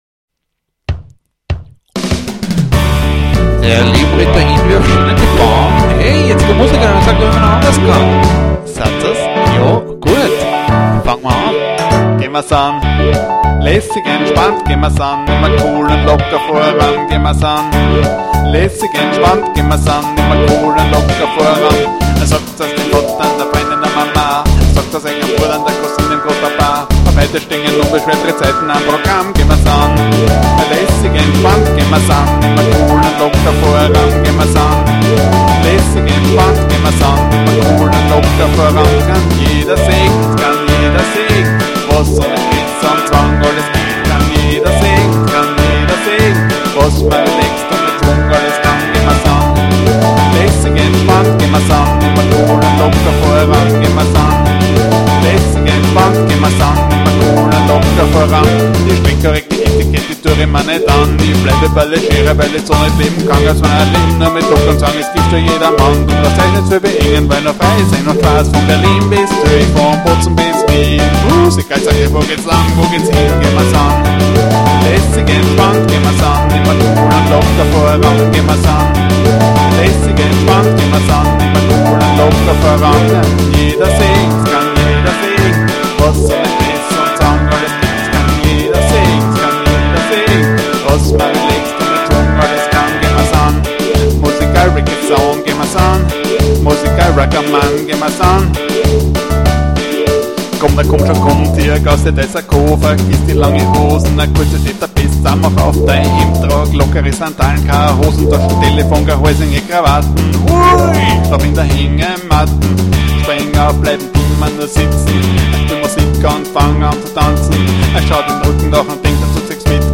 Die Coverversion einer Coverversion.
The coverversion of a coverversion.